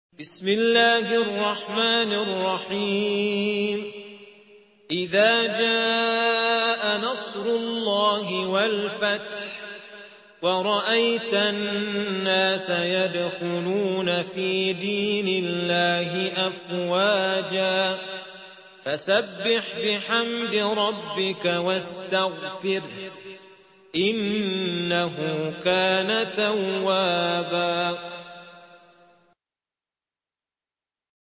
سورة النصر مدنية عدد الآيات:3 مكتوبة بخط عثماني كبير واضح من المصحف الشريف مع التفسير والتلاوة بصوت مشاهير القراء من موقع القرآن الكريم إسلام أون لاين